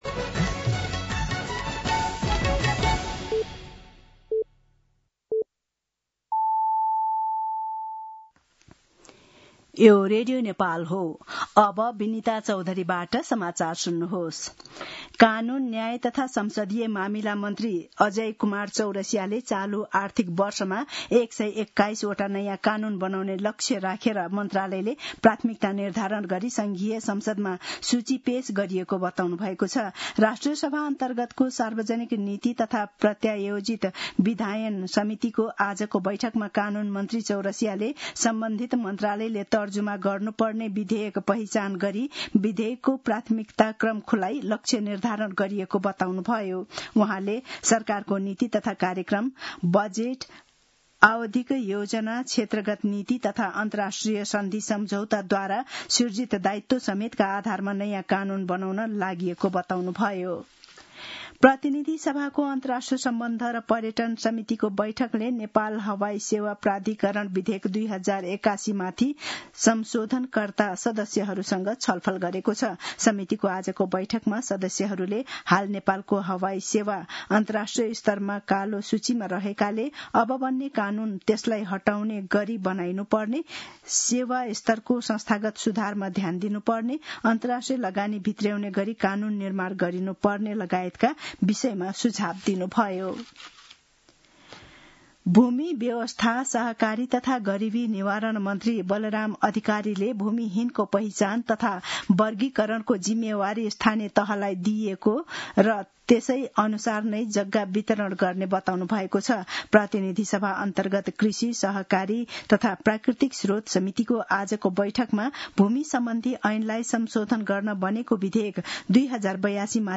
An online outlet of Nepal's national radio broadcaster
दिउँसो ४ बजेको नेपाली समाचार : १३ साउन , २०८२